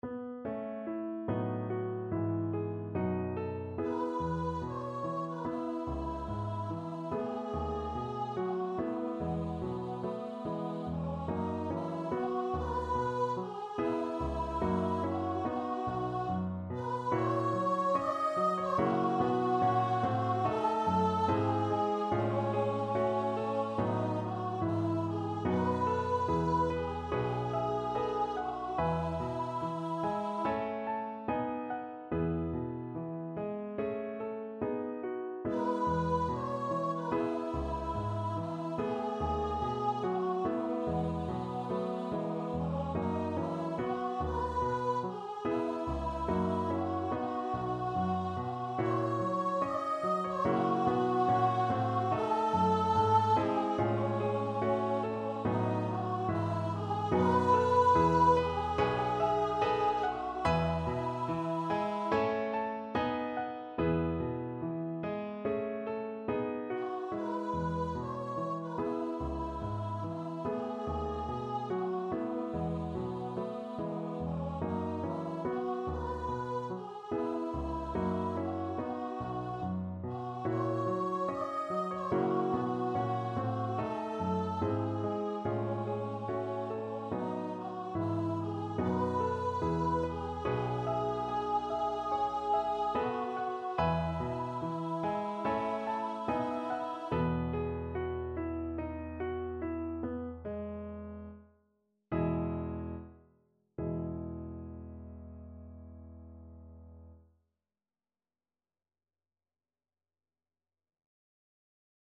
4/4 (View more 4/4 Music)
B4-Eb6
Classical (View more Classical Voice Music)